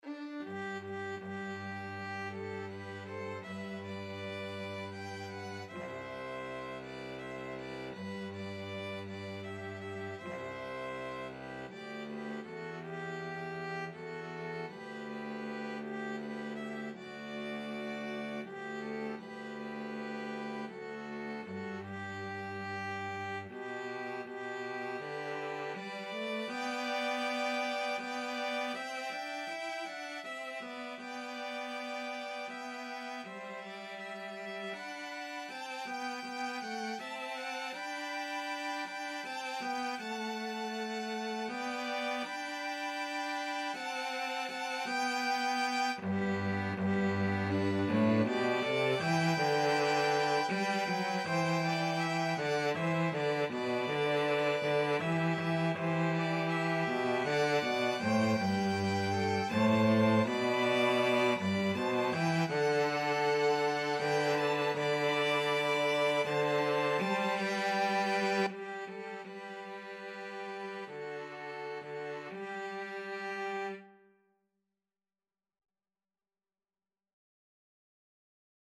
Free Sheet music for 2-Violins-Cello
"Oh Shenandoah" (also called simply "Shenandoah", or "Across the Wide Missouri") is a traditional American folk song of uncertain origin, dating at least to the early 19th century.
G major (Sounding Pitch) (View more G major Music for 2-Violins-Cello )
3/4 (View more 3/4 Music)
Andante